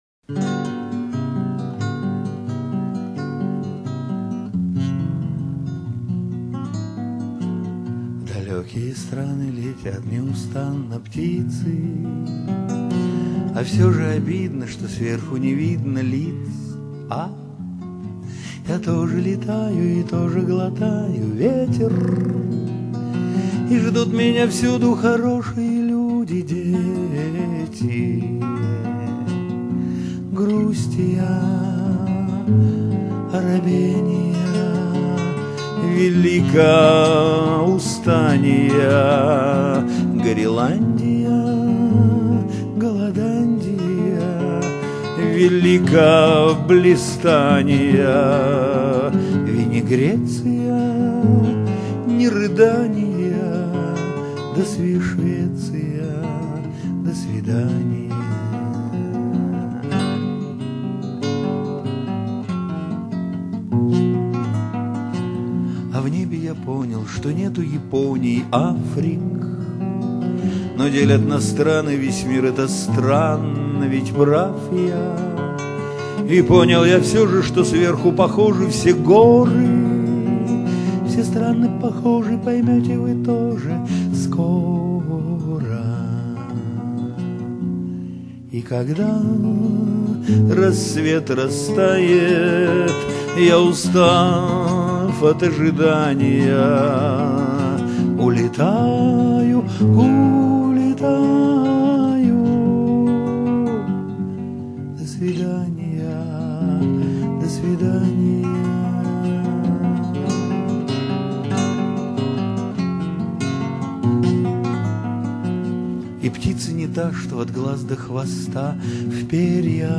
Песня в исполнении автора